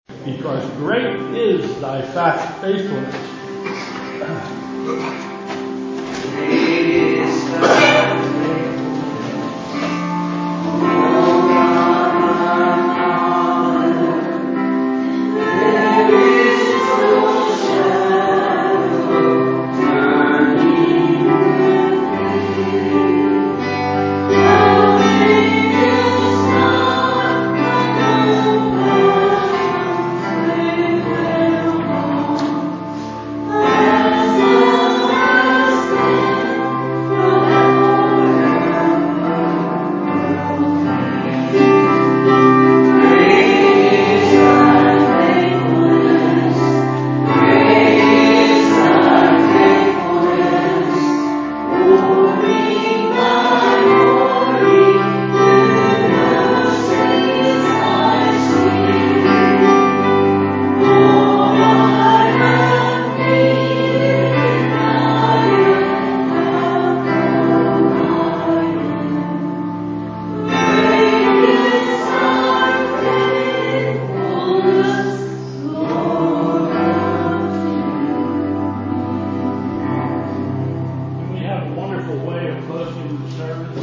Bethel Church Service
Closing Hymn: " Great is Thy Faithfulness"
....closing chorus: "Bind Us Together", and benediction